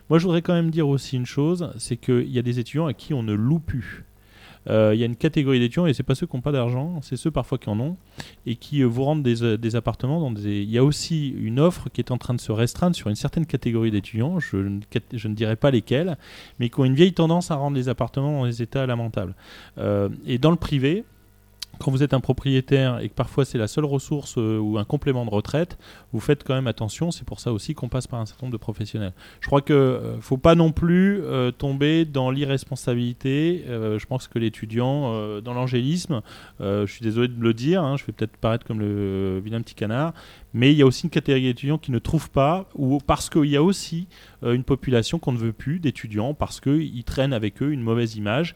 Ce jeudi 1er février, différents acteurs du logements étudiants se sont retrouvés autour d’une table pour parler de plusieurs aspects sur les habitations.